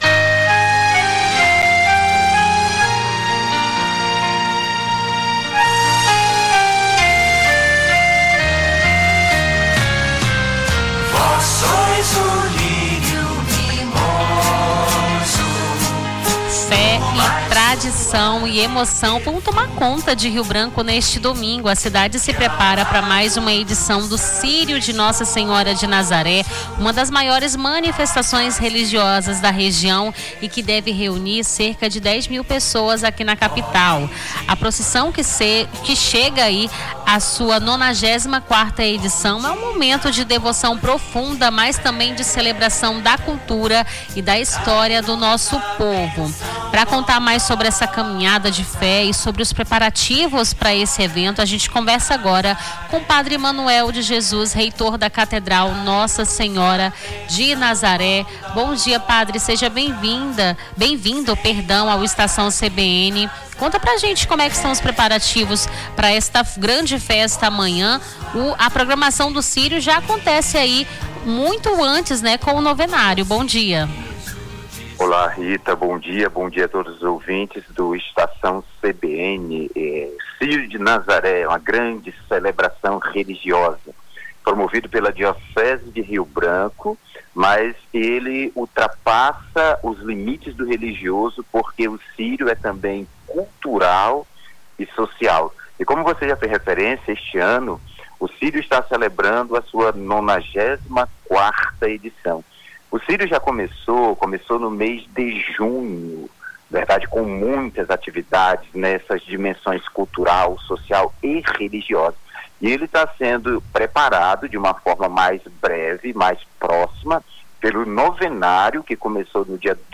ENTREVISTA PROGRAMAÇÃO CÍRIO